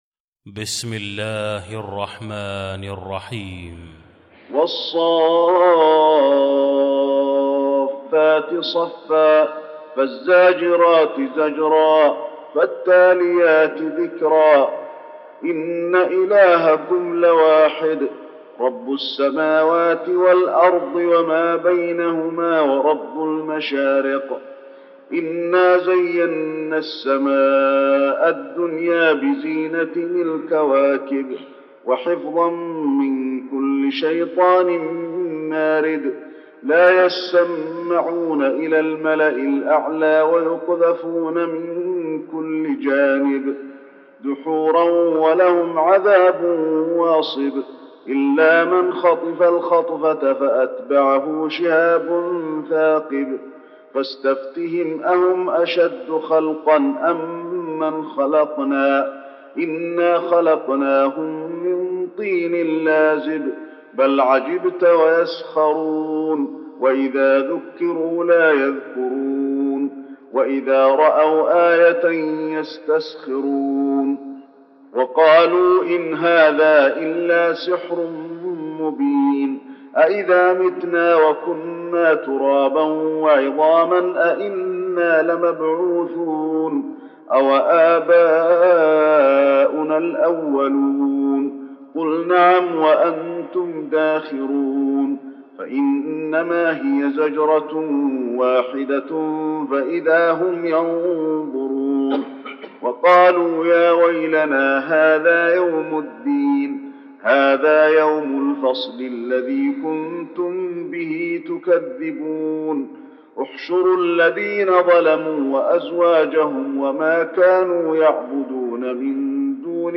المكان: المسجد النبوي الصافات